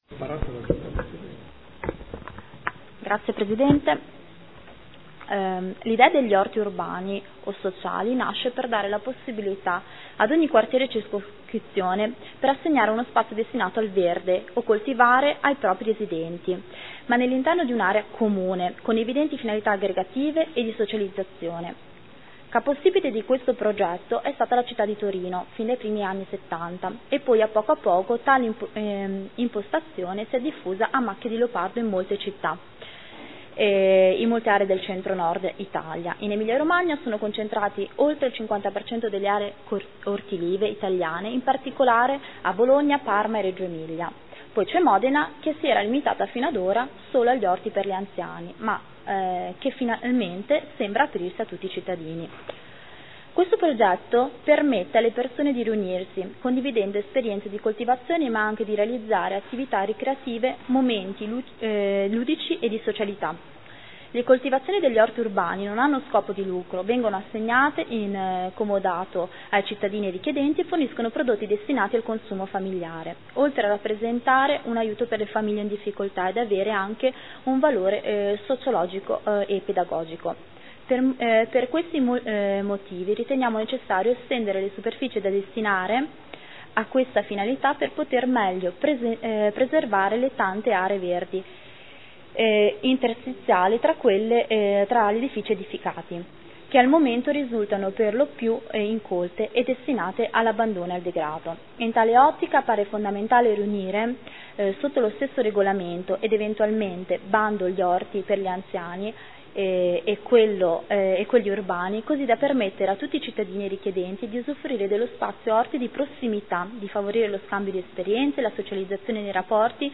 Elisabetta Scardozzi — Sito Audio Consiglio Comunale
Seduta del 23/07/2015 Dichiarazione di voto.